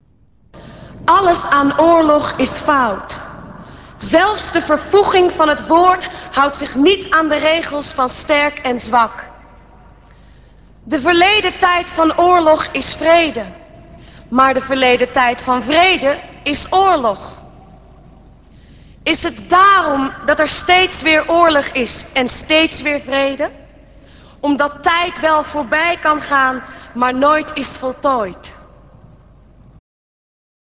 Tijdens de Dodenherdenking op 4 mei 1997 om 8 uur werd alles duidelijk: er zat een hele strategie achter, want daar verscheen op het podium, ten overstaan van het ganse volk, Trijntje Oosterhuis van de popgroep Total Touch, die iets zou voorlezen. Het was een gedicht waarvan de belangrijkste gedachte was dat de verleden tijd van oorlog vrede is, en omgekeerd.
In de eerste plaats omdat ze sprak van de vierde maai, en alle andere ei's en ij's dezelfde behandeling gaf, maar ook om het interessante aspect dat haar spraak door het Nationaal Comité kennelijk officieel geaccepteerd werd.
Ik moet wel opmerken dat wat we toen hoorden een spreekster was die voorlas en zorg besteedde aan haar spraak.